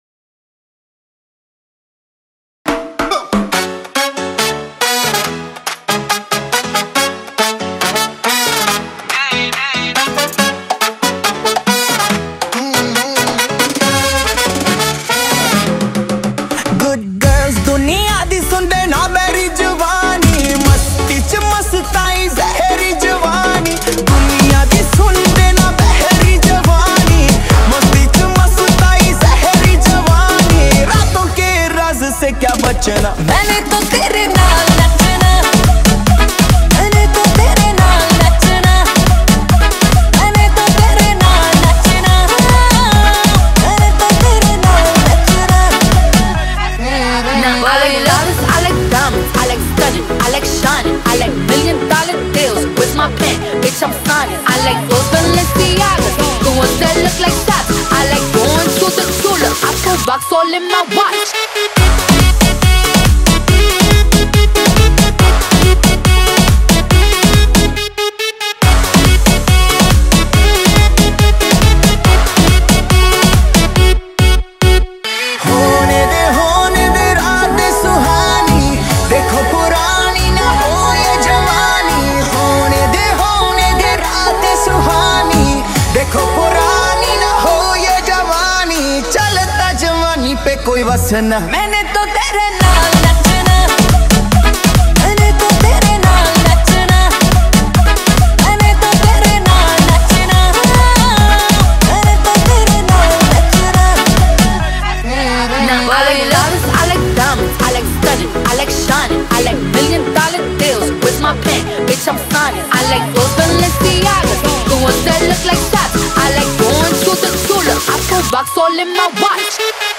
• Mix Type: Club Mix / Clean Bass
• Category: Bollywood DJ Remix
• 🔊 Clean Bass Boosted Audio